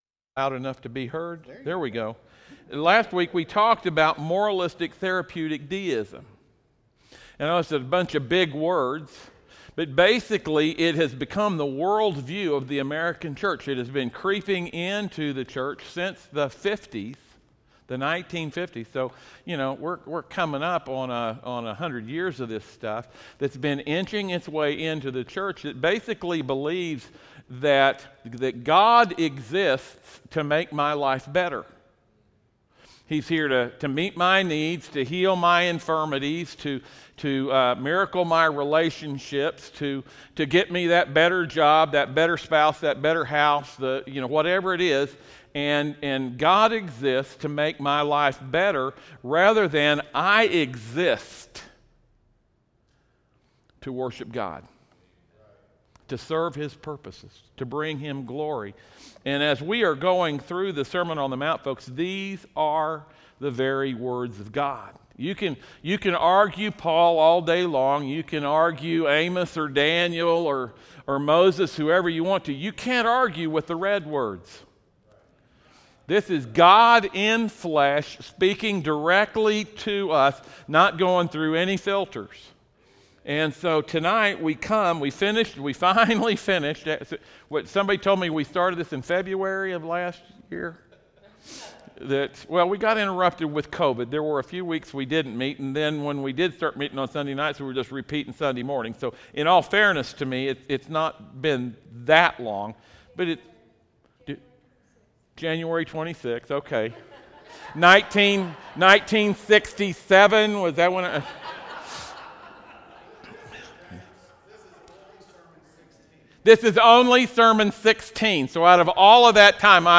Service Type: audio sermons